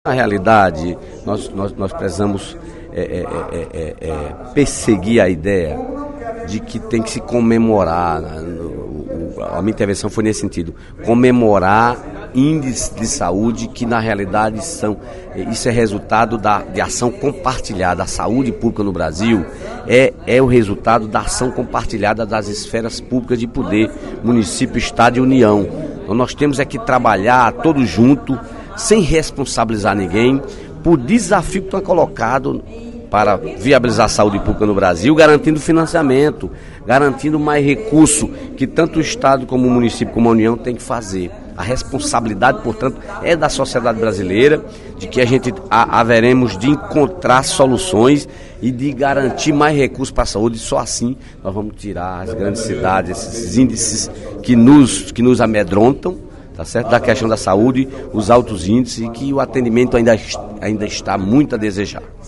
Na sessão plenária desta sexta-feira (02/03), o deputado Dedé Teixeira (PT) ocupou a tribuna da Assembleia Legislativa para rebater as críticas feitas por Fernando Hugo (PSDB) à prefeitura de Fortaleza.